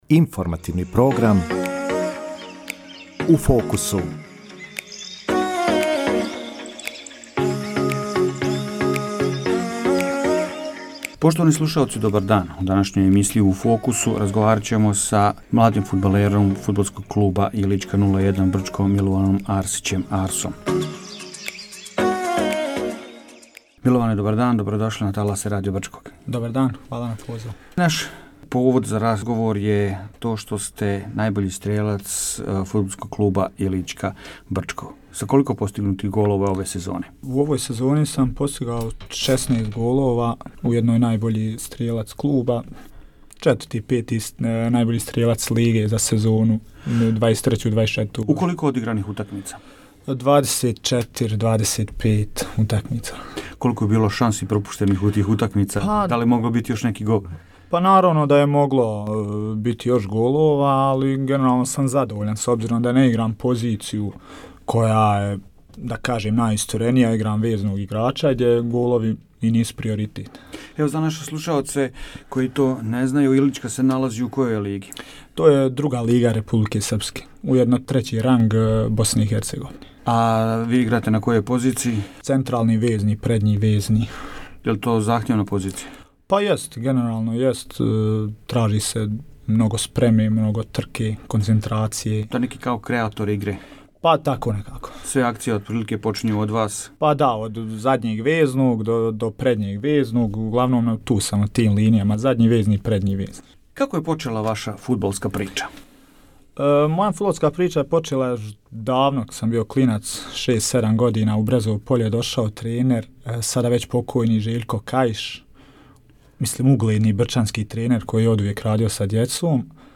Гост емисије “У фокусу”